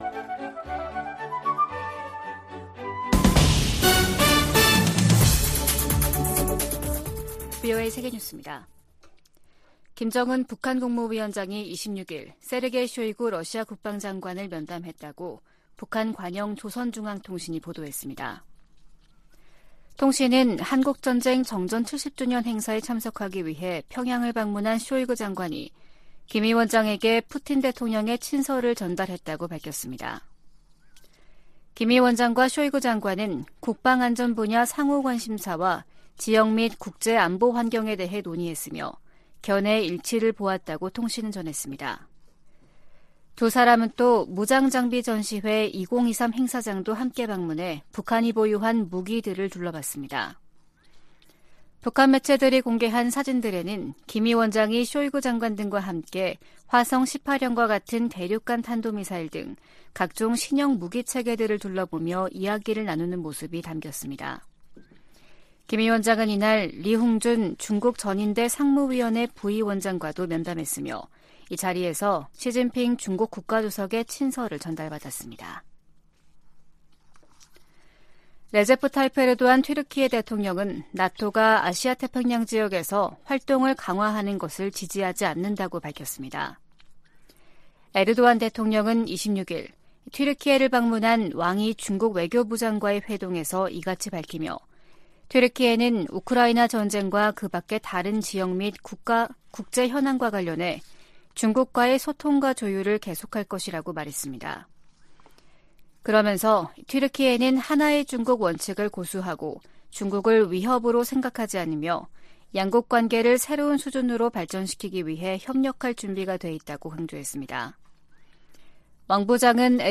VOA 한국어 아침 뉴스 프로그램 '워싱턴 뉴스 광장' 2023년 7월 28일 방송입니다. 김정은 북한 국무위원장이 러시아 국방장관과 함께 '무장장비 전시회-2023'를 참관했습니다. 백악관은 북한과 러시아의 무기 거래 가능성에 관해 누구도 러시아의 우크라이나 전쟁을 지원해서는 안된다고 강조했습니다. 조 바이든 미국 대통령이 7월 27일을 한국전 정전기념일로 선포하고, 미한동맹이 세계 평화와 번영에 기여하도록 노력하자고 말했습니다.